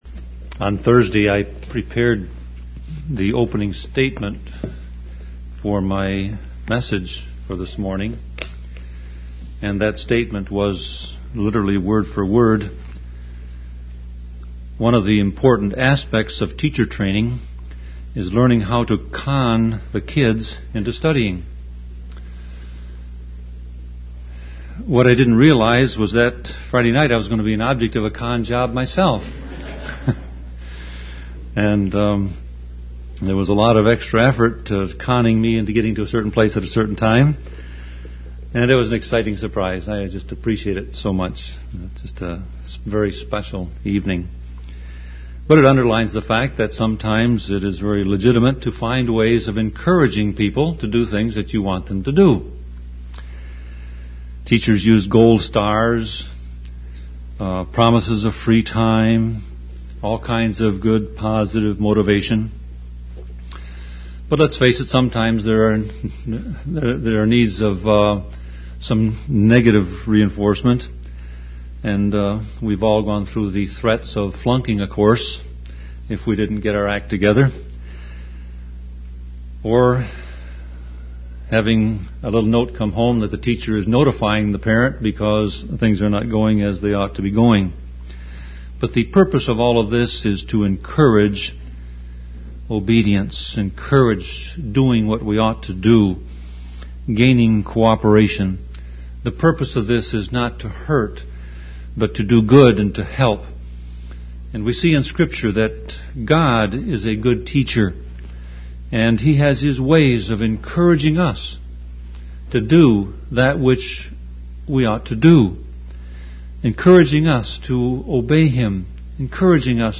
Sermon Audio Passage: Genesis 43 Service Type